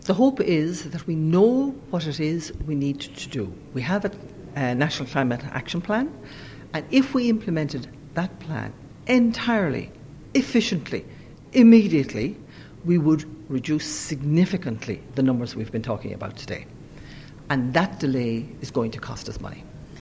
Chair of the Climate Change Advisory Council, Marie Donnelly says although progress is being made it’s not fast enough………….